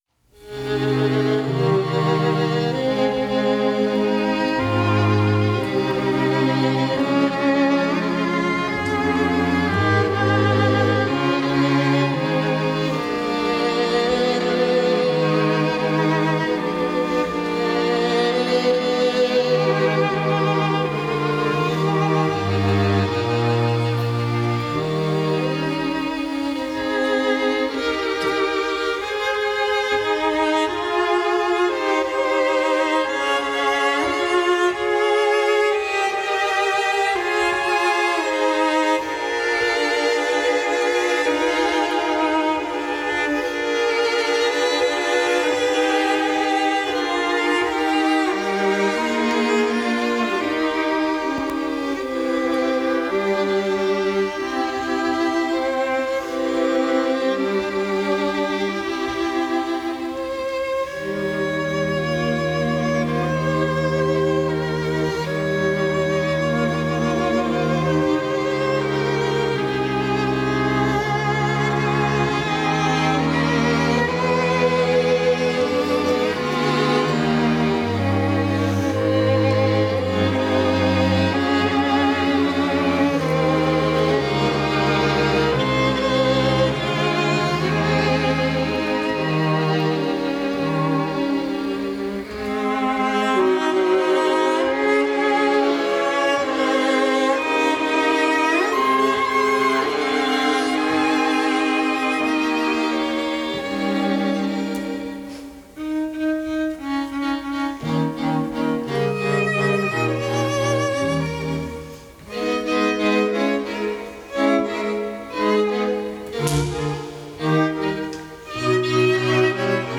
LiveARTS String Quartet